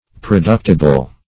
Search Result for " productible" : The Collaborative International Dictionary of English v.0.48: Productible \Pro*duct"i*ble\, a. [Cf. F. productible.] Capable of being produced; producible.
productible.mp3